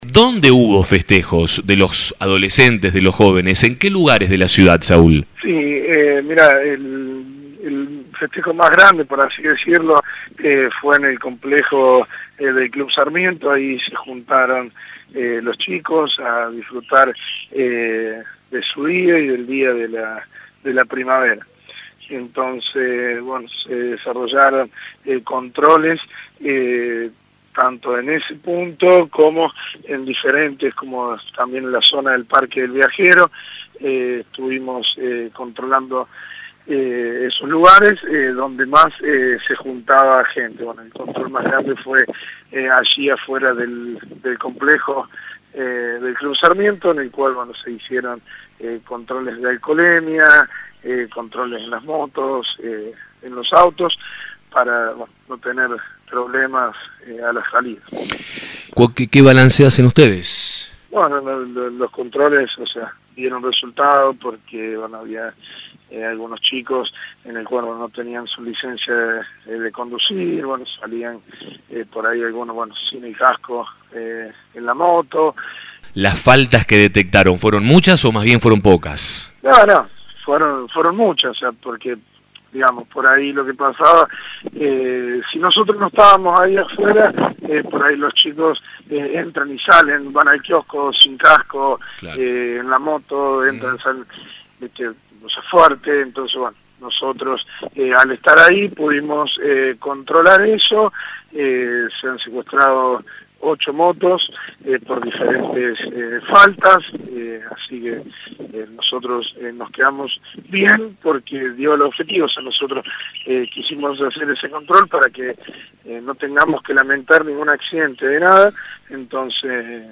Saúl Arbarello, titular del área municipal, en conversaciones con La Mañana dio detalles de los operativos: